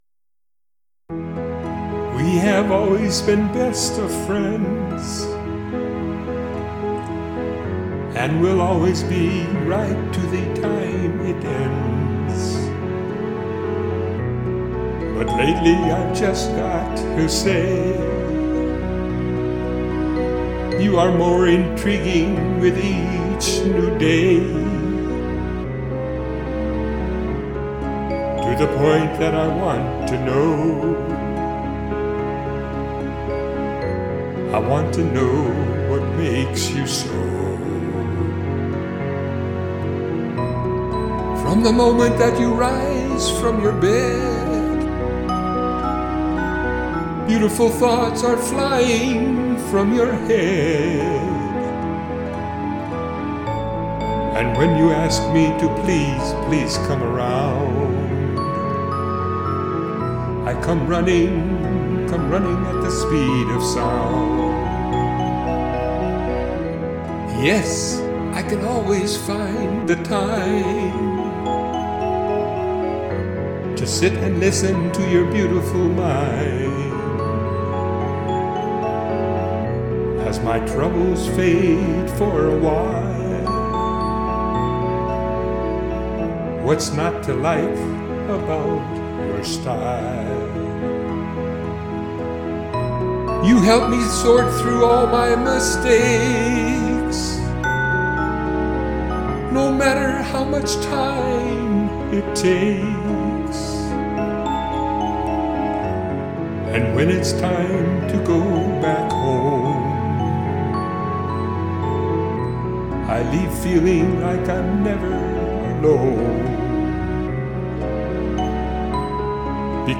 Easy listening!